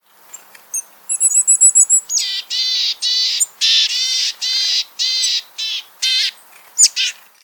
Art: Granmeis (Poecile montanus)
Lokkelyd
Lyder: Granmeisen har en karakteristisk nasal og grov «tææh tææh»-lyd, men også tynne «ti-ti» – eller «siu-siu-siu-siu»-lyder.
granmeis_-_poecile_montanus_borealis.mp3